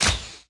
Media:ArcherQueen_base_atk_1.wav 攻击音效 atk 初级和经典及以上形态攻击音效
ArcherQueen_base_atk_1.wav